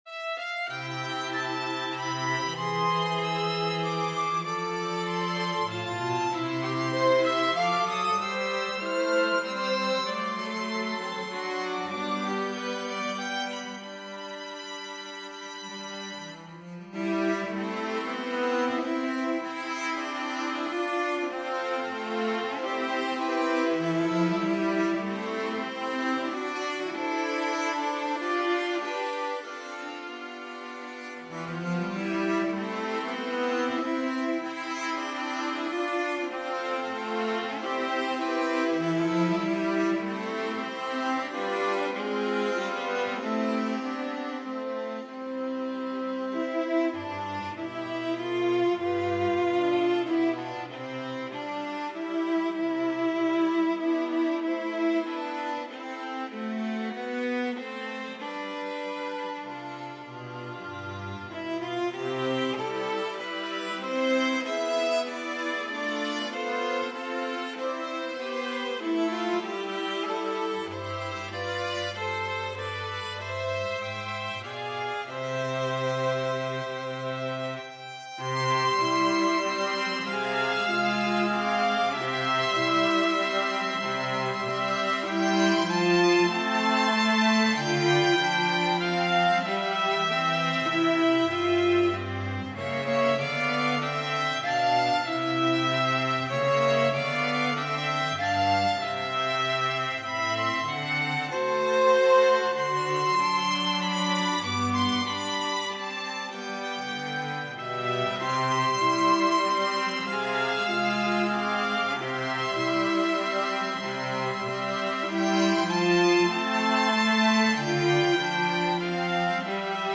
a traditional Irish Ballad, arranged here for string quartet